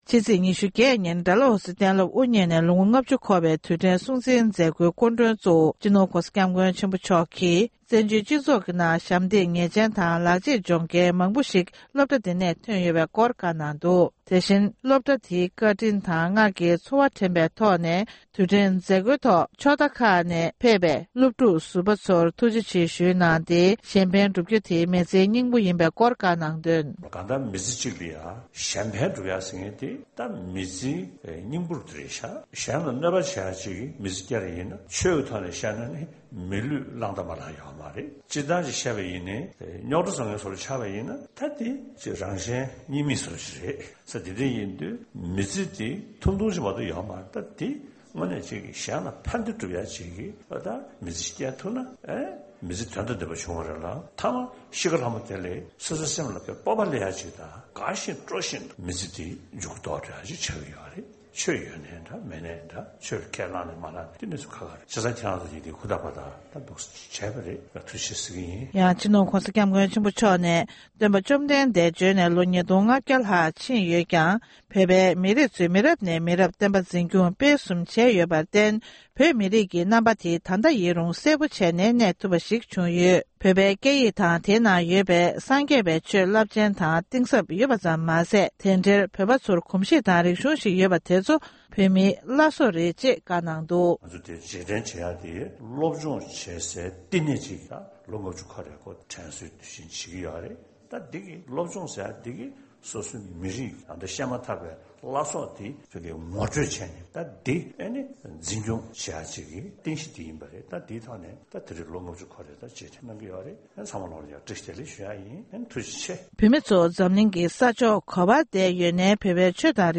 ༸གོང་ས་༸སྐྱབས་མགོན་ཆེན་པོ་མཆོག་ནས་ཌ་ལ་ཧོ་སི་གཏན་སློབ་དབུ་བརྙེས་ནས་ལོ་༥༠མཛད་སྒོའི་སྐབས་བཀའ་སློབ་སྩལ་བ།